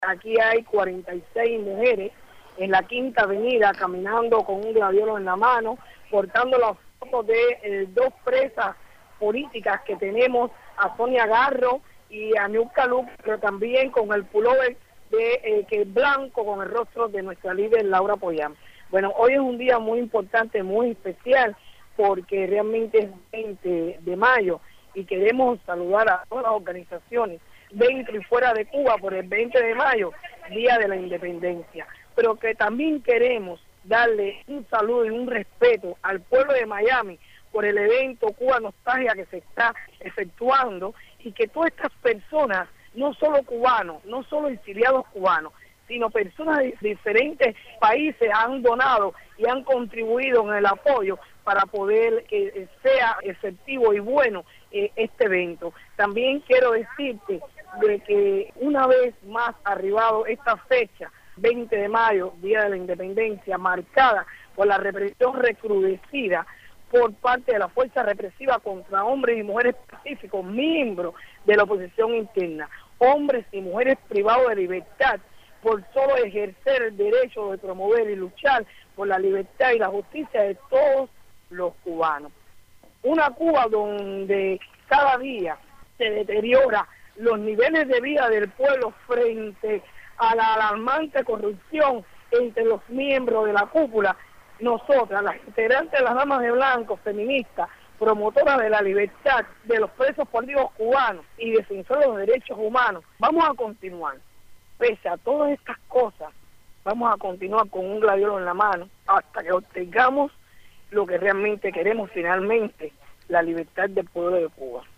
Declaraciones de Berta Soler en el Día de la Independencia